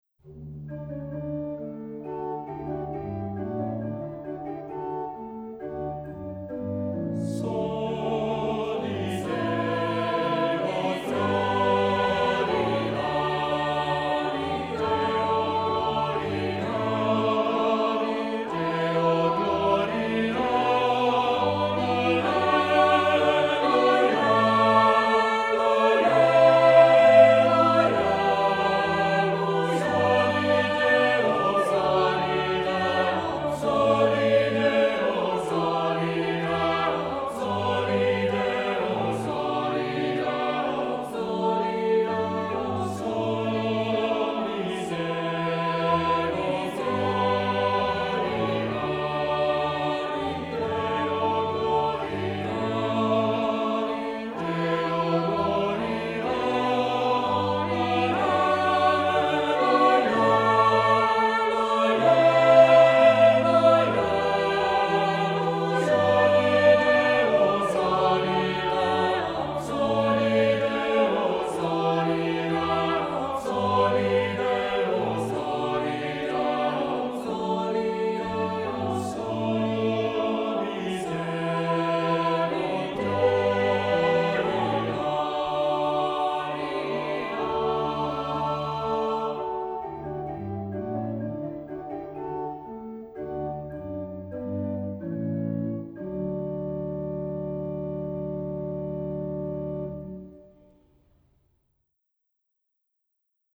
Accompaniment:      Keyboard
Music Category:      Early Music